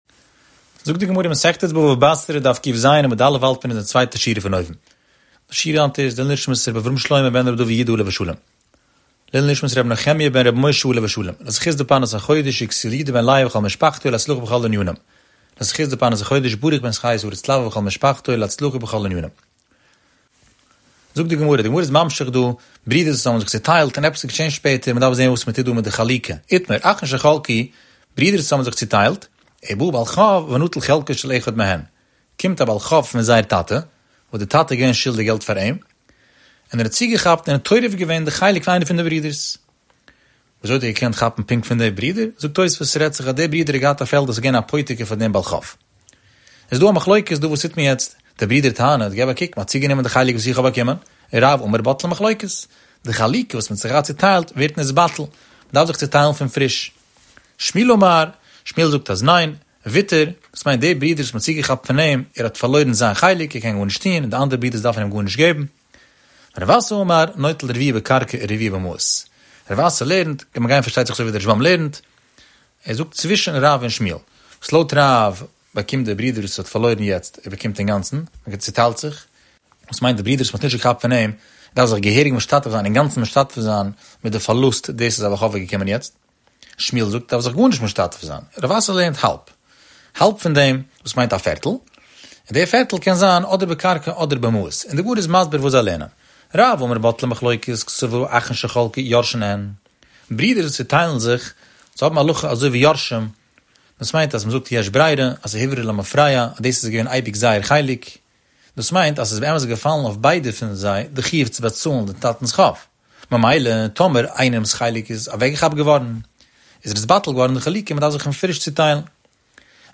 The daily daf shiur has over 15,000 daily listeners.